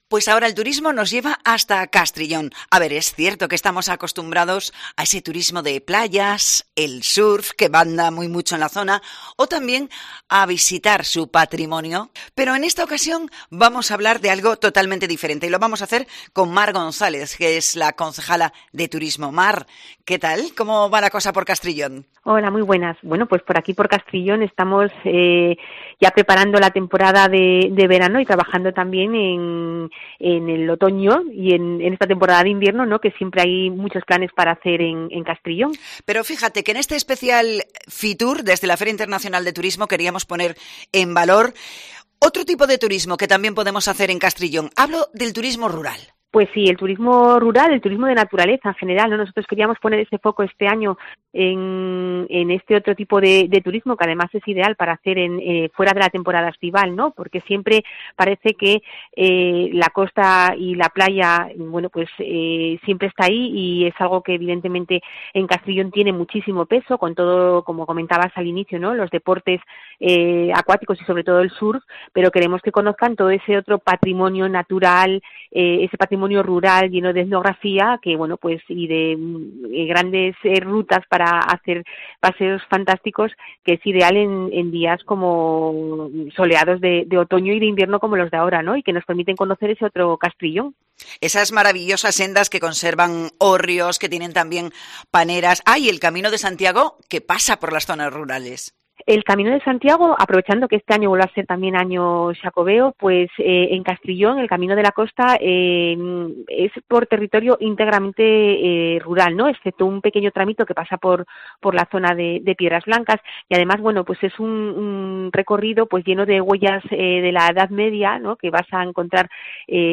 La concejala de Turismo de este municipio costero, Mar González, ha estado en el especial de COPE Asturias con motivo de la Feria Internacional del Turismo
Fitur 2022: Entrevista a Mar González, alcaldesa de Castrillón